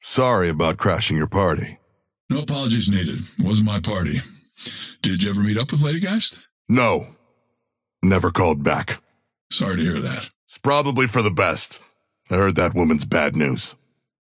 Abrams and Dynamo Oathkeeper conversation 2